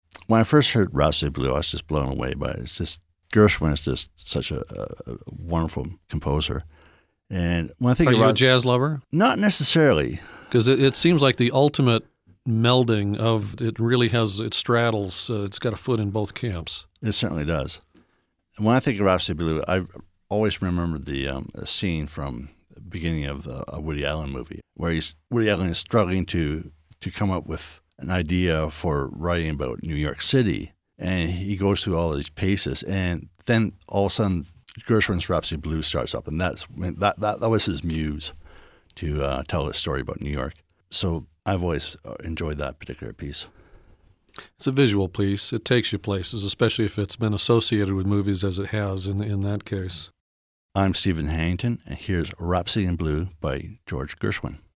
The interview recording studio at Classical 89.